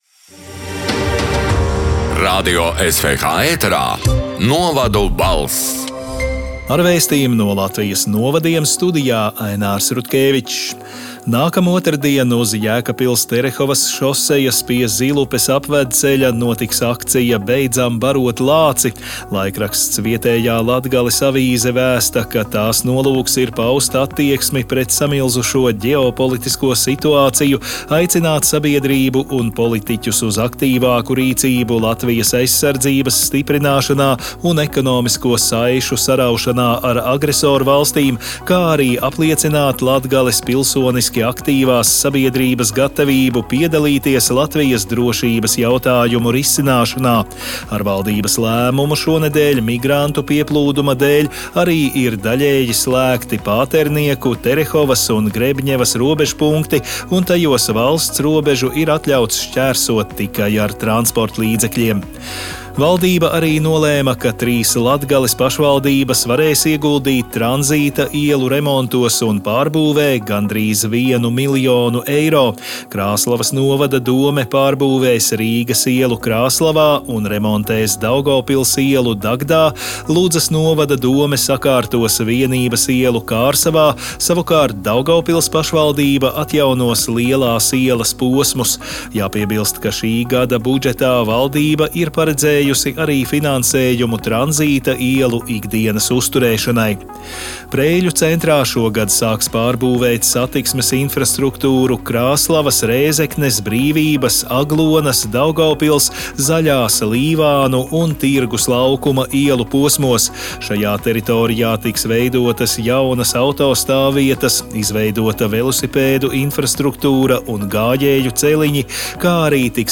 Radio SWH ēterā divreiz nedēļā izskan ziņu raidījums “Novadu balss”, kurā iekļautas Latvijas reģionālo mediju sagatavotās ziņas. Raidījumā Radio SWH ziņu dienests apkopo aktuālāko no laikrakstiem “Auseklis”, “Kurzemes Vārds”, “Zemgales Ziņas” un ”Vietējā Latgales Avīze”.
“Novadu balss” 21. marta ziņu raidījuma ieraksts: